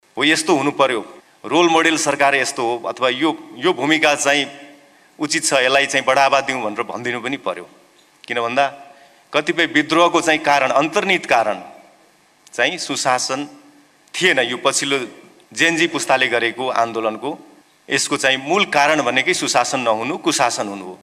कार्यक्रममा गृहमन्त्री ओमप्रकाश अर्यालले सुशासनका लागि प्रधानमन्त्री र मन्त्रीहरू सक्रिय रूपमा लागिरहेकाले अब विगतको जस्तो अवस्था नदोहोरिने बताए।